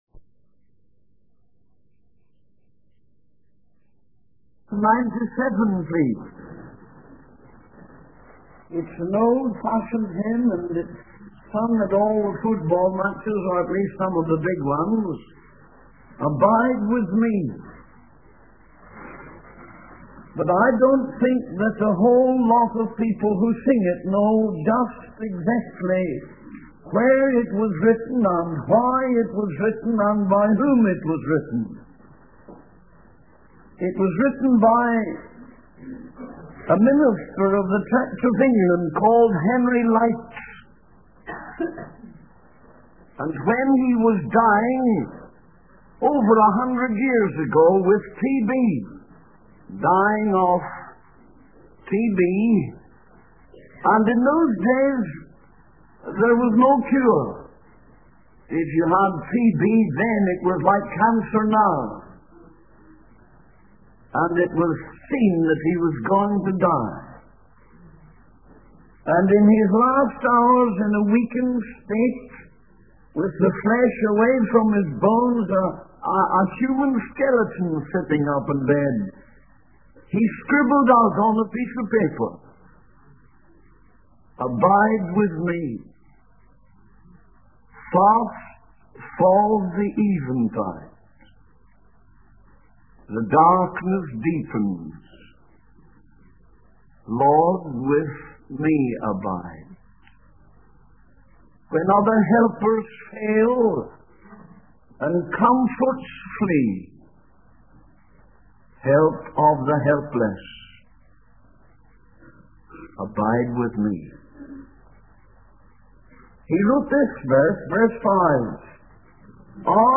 In this sermon, the preacher focuses on the first five verses of Revelation chapter 7.